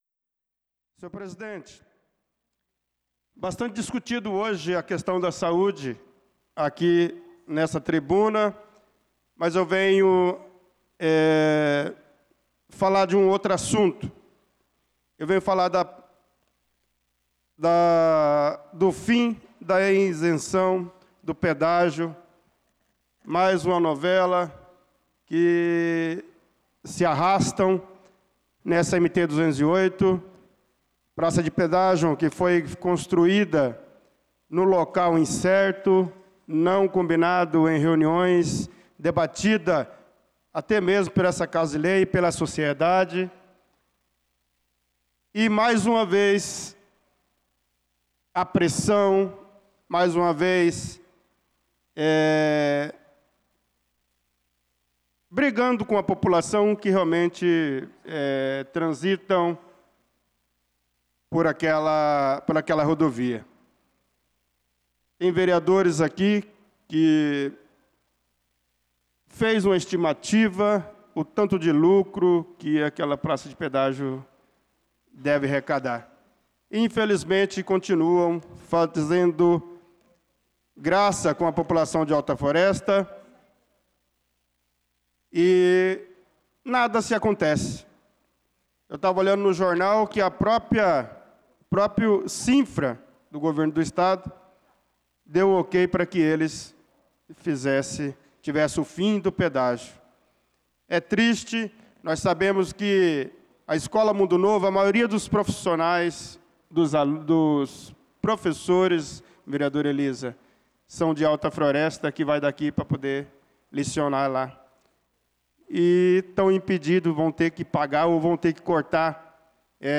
Pronunciamento do vereador Bernardo Patrício na Sessão Ordinária do dia 05/05/2025